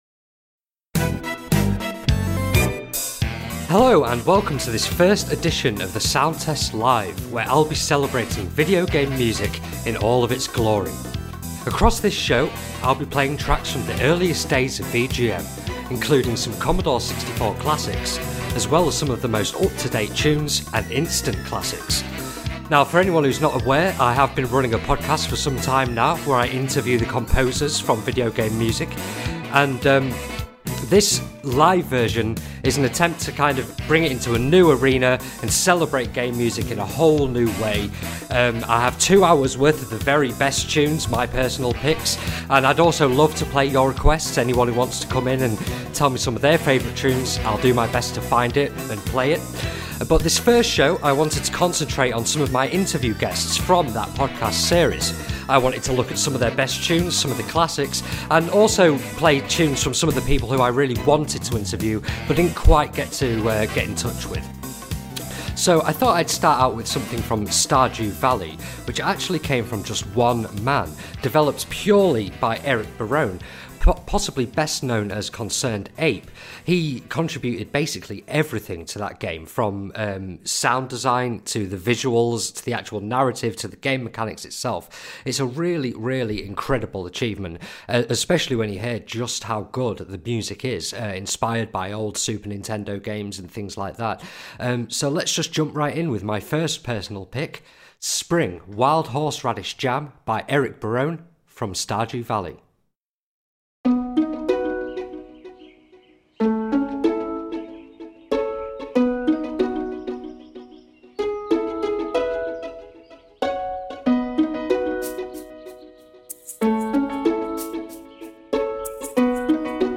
Previously, this episode was only available to patrons, but I've given it a clean up and posted it here ready for the usual stream time. Essentially, I've replaced the music with better quality rips and tweaked the volumes of our chat slightly.